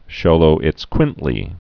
(shōlō-ĭts-kwĭntlē, -ēts-)